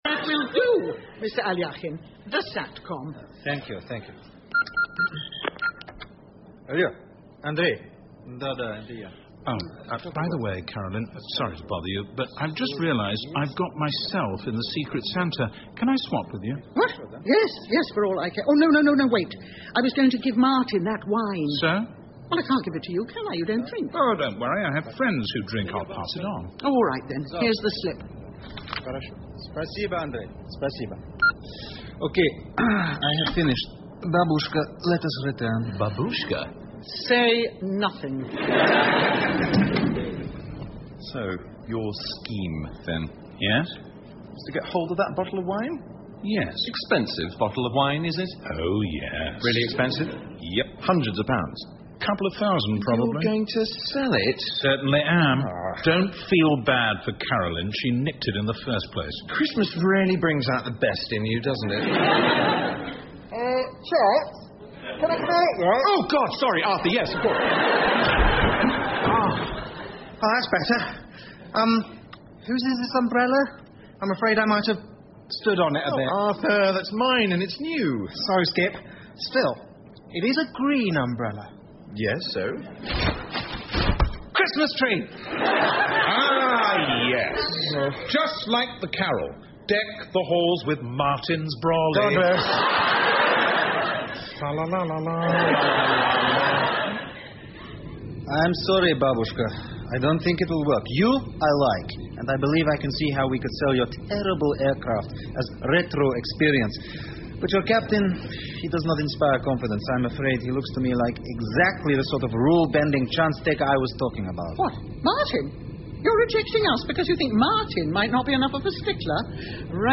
英文广播剧在线听 Cabin Pressure - 05 听力文件下载—在线英语听力室